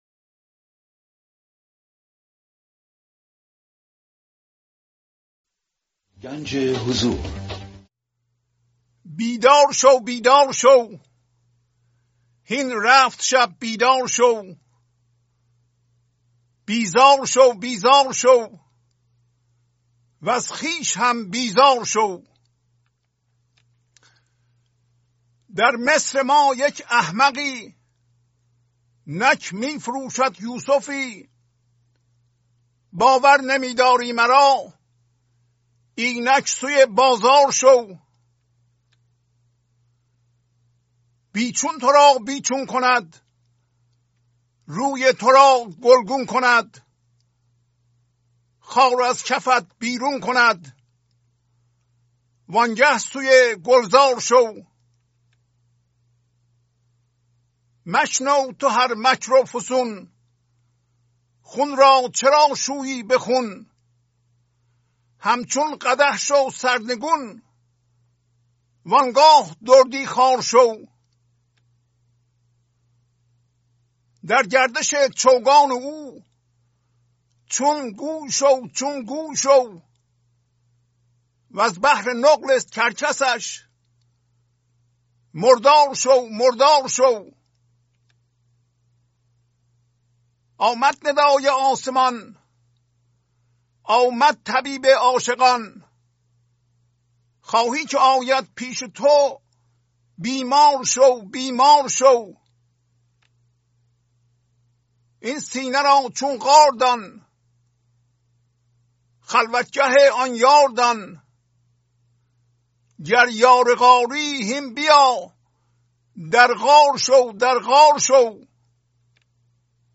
خوانش تمام ابیات این برنامه - فایل صوتی
930-Poems-Voice.mp3